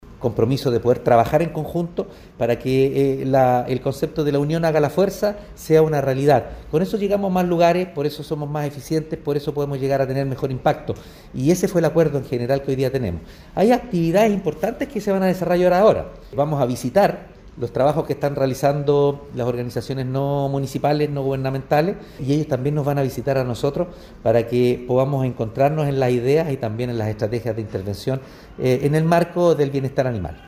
Alcalde-Oscar-Calderon-Sanchez-1-2.mp3